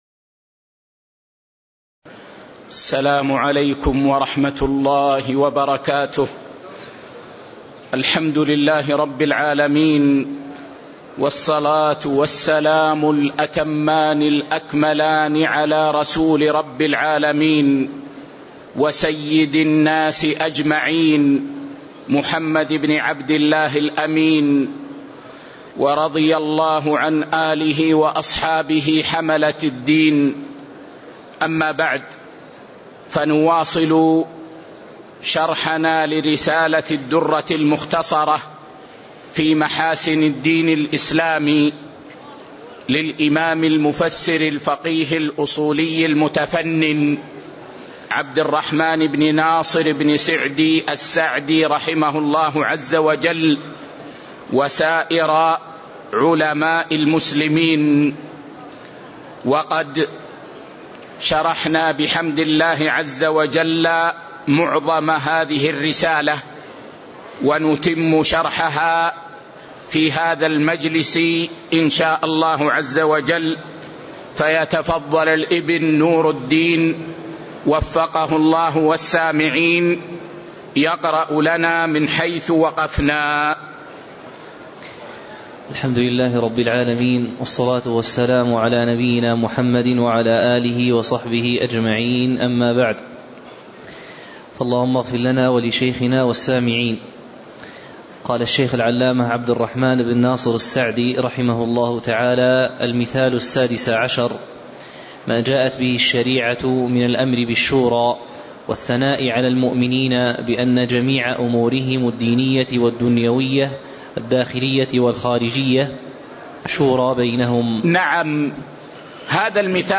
تاريخ النشر ١٨ شعبان ١٤٤٤ المكان: المسجد النبوي الشيخ